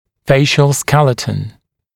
[‘feɪʃ(ə)l ][‘фэйш(э)л ]лицевой отдел черепа